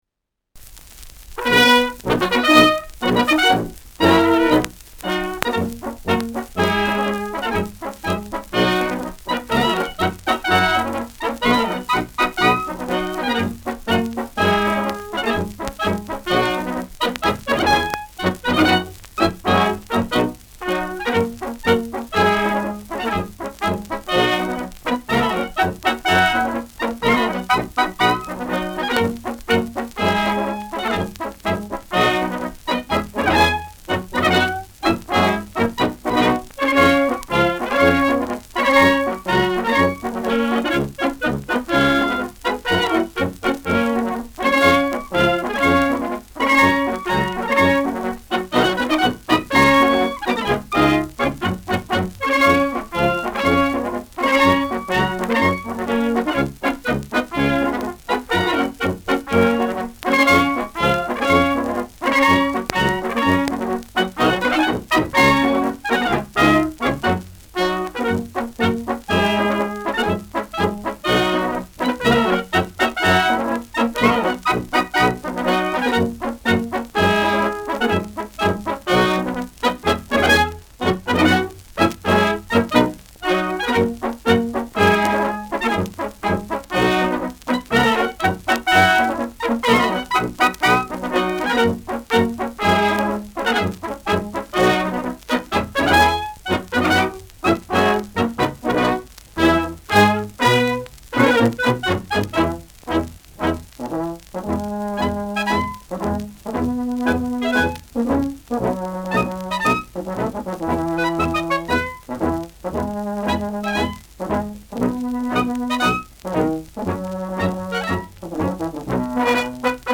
Schellackplatte
leichtes Knistern : leichtes Knacken bei 0’05’’ : vereinzelt leichtes Knacken
Innviertler Bauernkapelle Solinger (Interpretation)
[Wien] (Aufnahmeort)